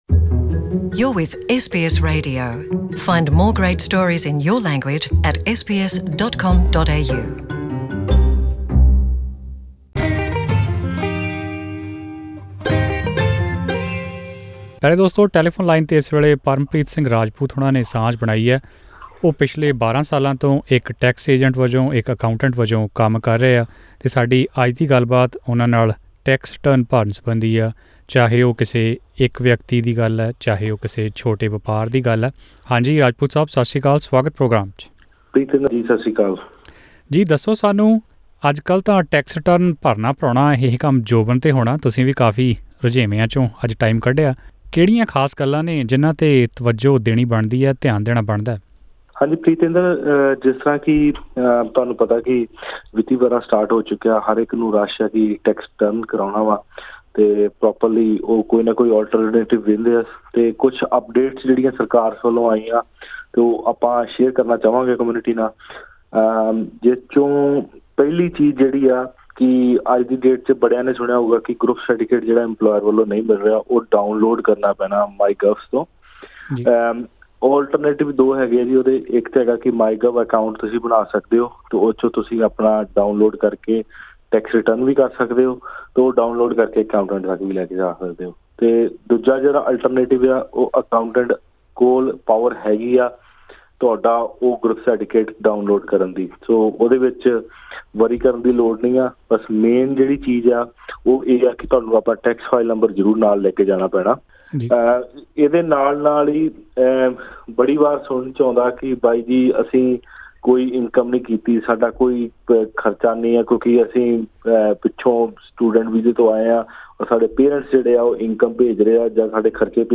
SBS Punjabi spoke to a tax expert who has been working in this field for over 12 years.